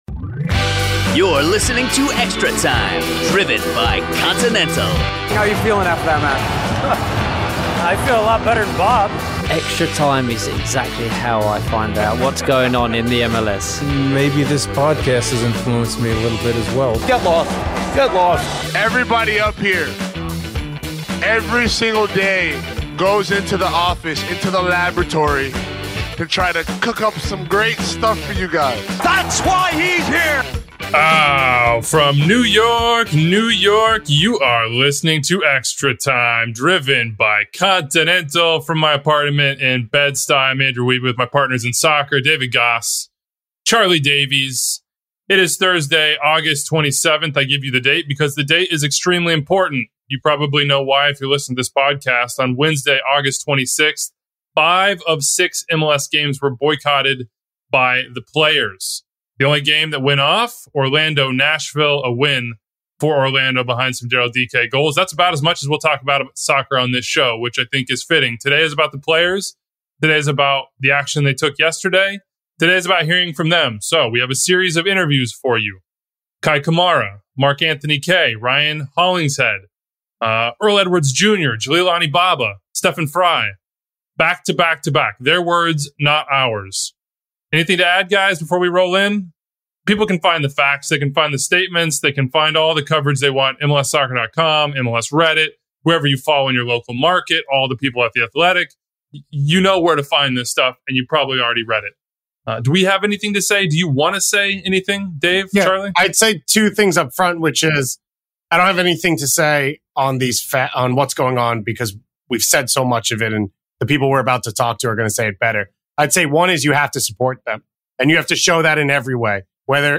5:20 – INTERVIEW: Kei Kamara, Colorado Rapids